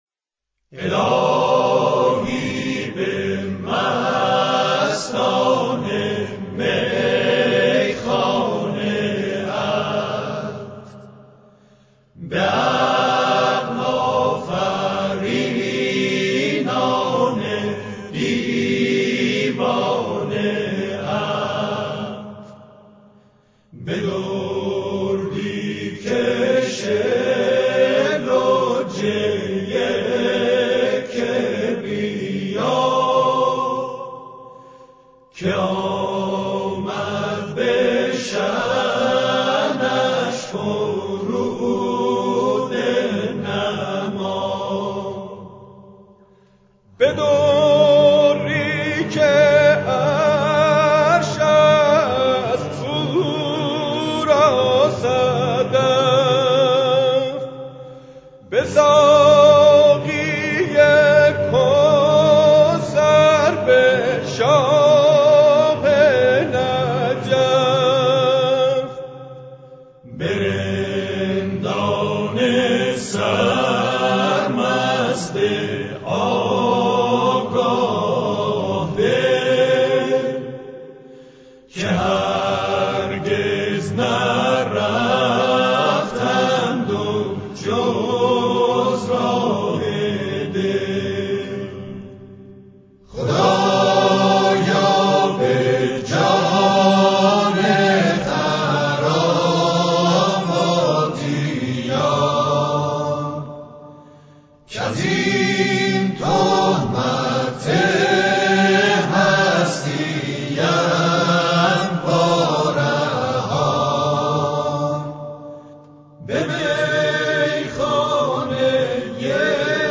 موسیقی عرفانی